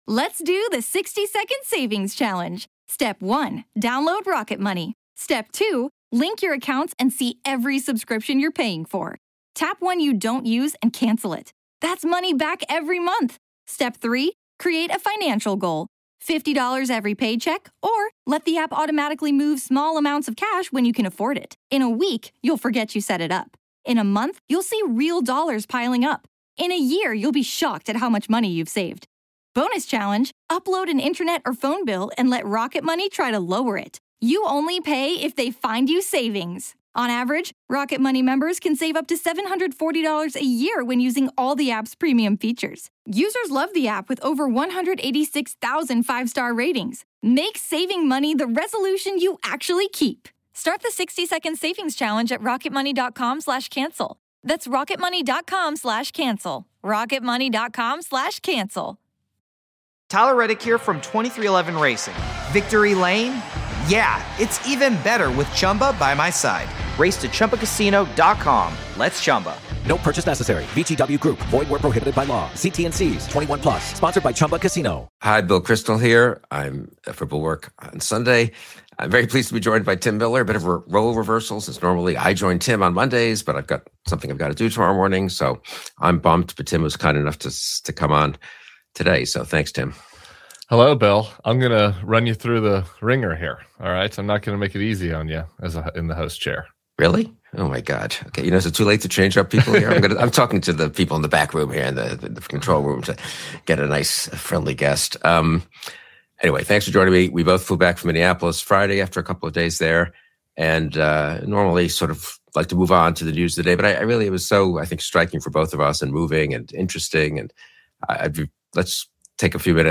Bill Kristol and Tim Miller go live on Sunday—fresh off their Minneapolis trip.